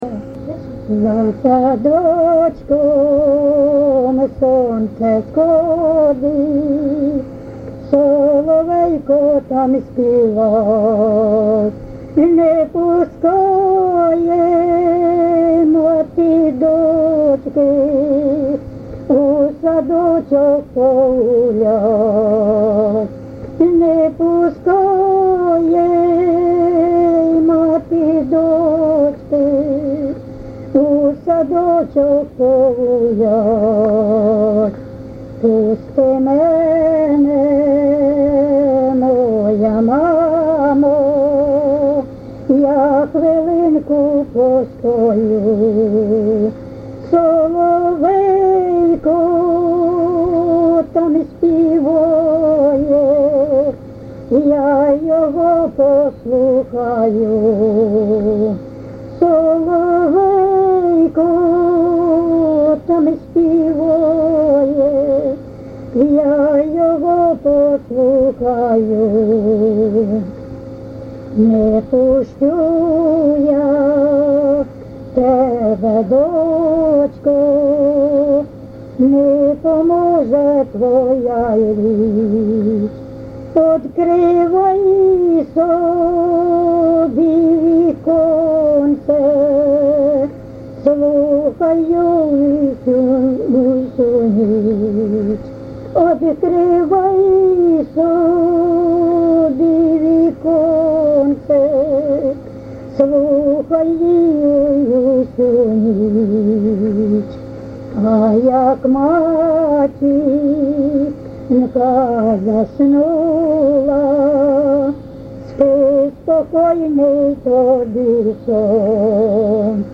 ЖанрПісні з особистого та родинного життя
Місце записус. Коржі, Роменський район, Сумська обл., Україна, Слобожанщина